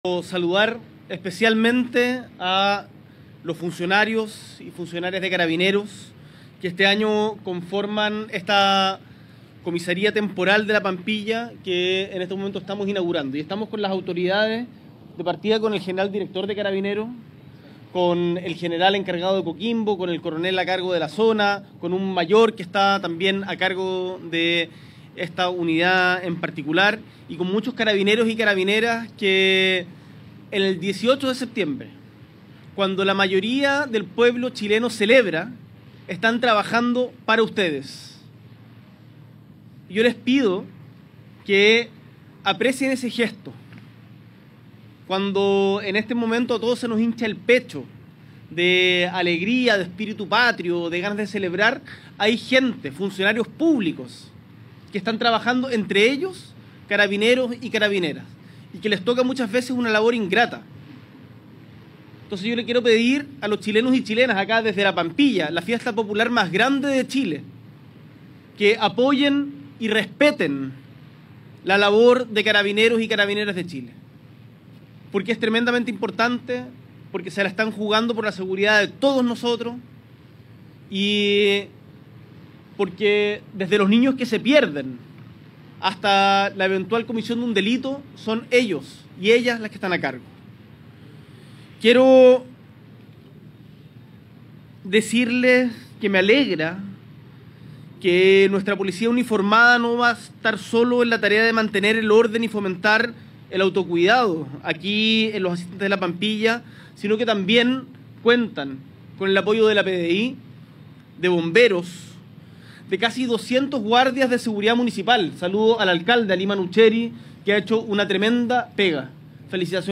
Este lunes 15 de septiembre, en el marco de la ceremonia de apertura de la Fiesta de La Pampilla 2025, el Presidente de la República, Gabriel Boric Font, encabezó la inauguración de la Comisaría Temporal de La Pampilla.